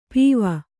♪ pīva